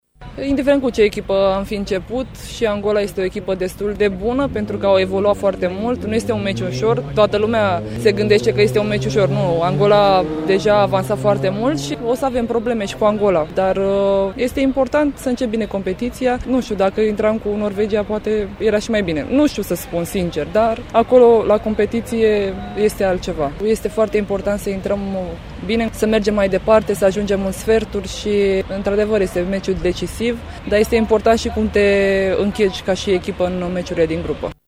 într-o declarație